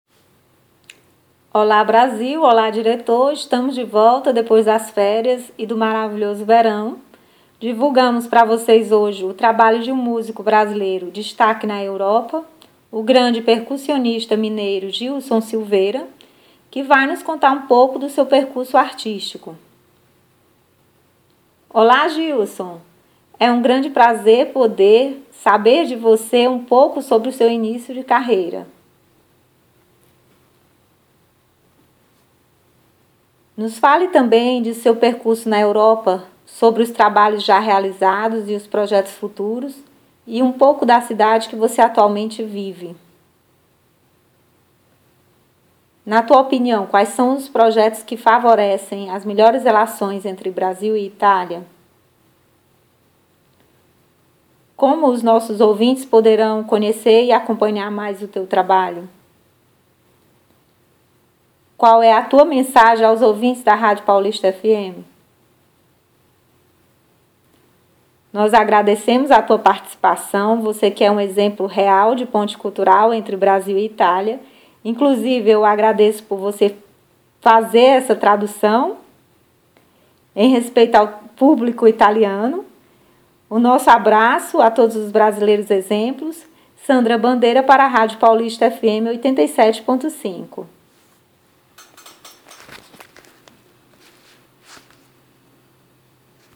Enyrevista